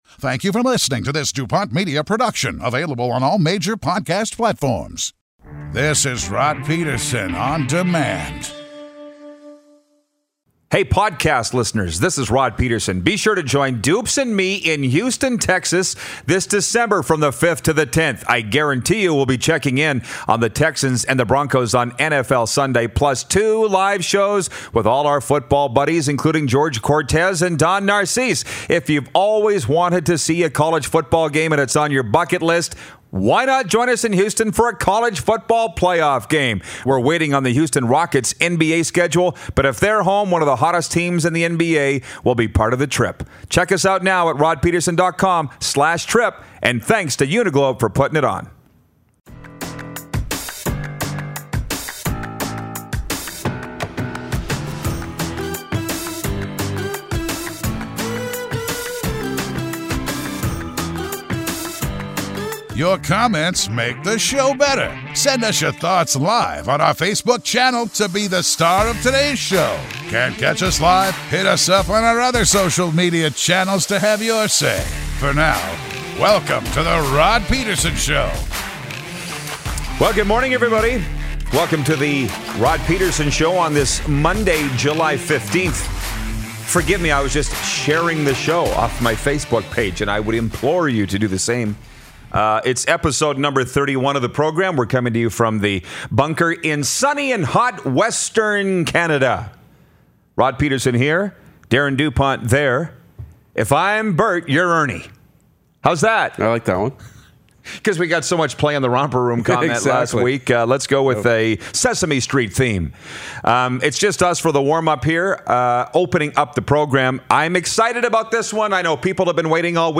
In studio today
We also get some calls